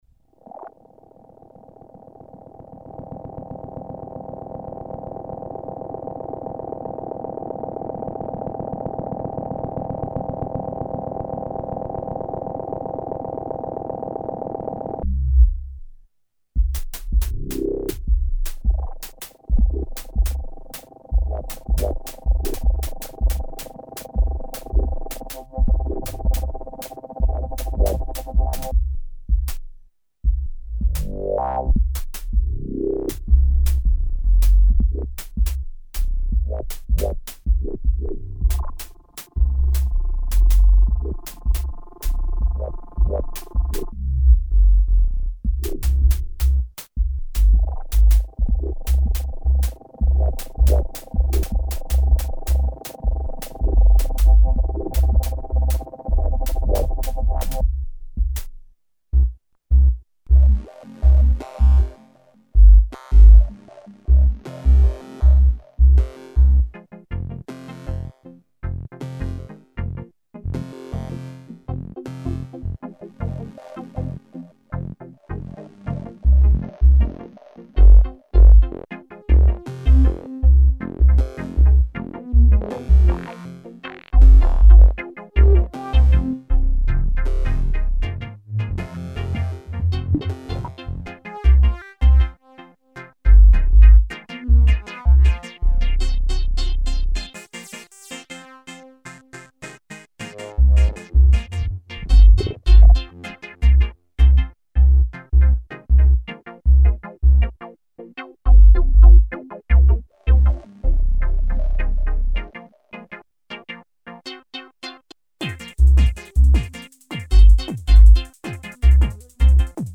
8 voices polyphonic synthesizer
Factory demo